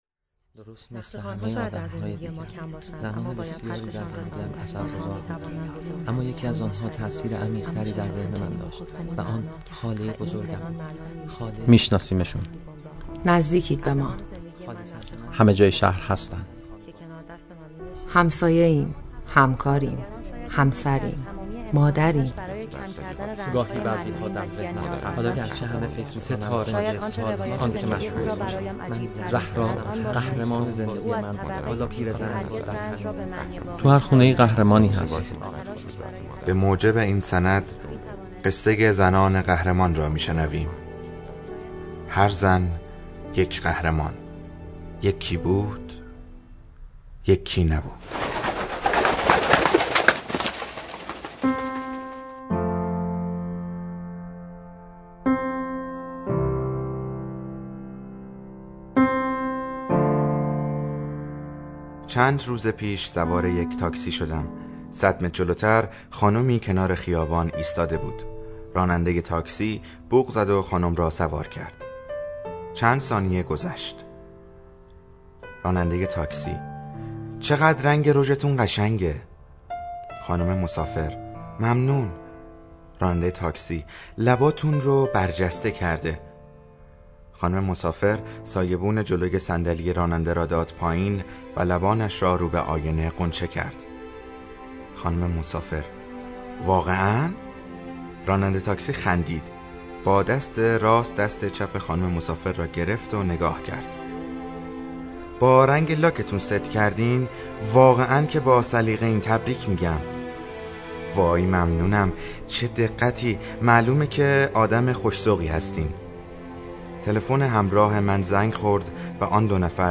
دکلمه ی بسیار زیبای انار فصل ندارد